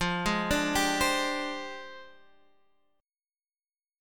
F 6th Suspended 2nd Flat 3rd